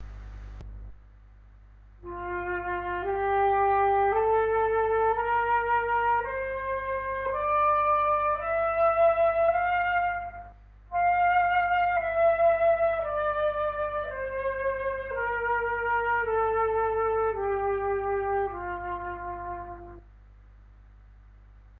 Son do máis natural!
O certo é que produce unha sensación de calma co seu timbre cálido e natural.
F-durskala.mp3